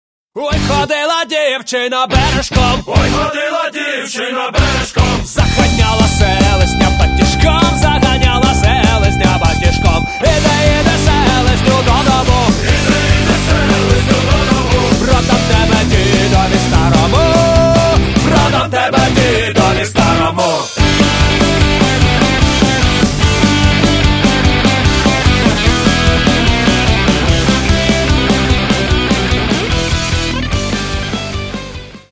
Catalogue -> Rock & Alternative -> Folk Rock